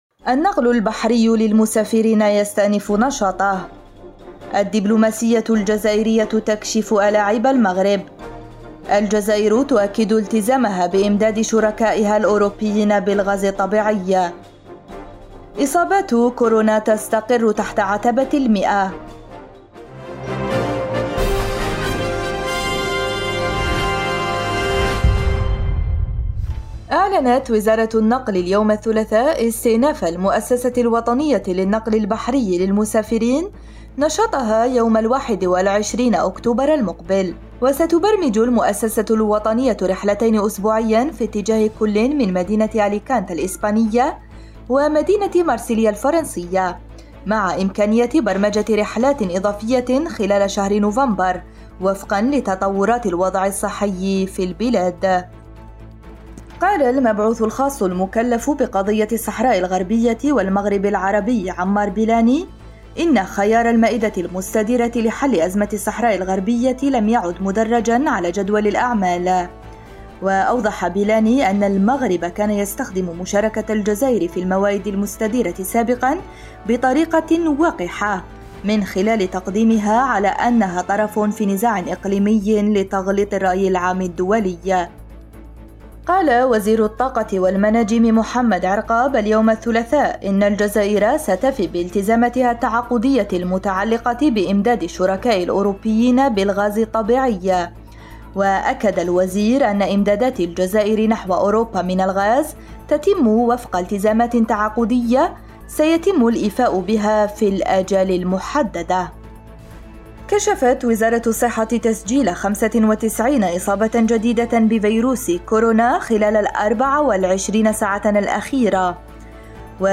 النشرة اليومية: الدبلوماسية الجزائرية تكشف ألاعيب المغرب “الوقحة” – أوراس
النشرة الرقميةفي دقيقتين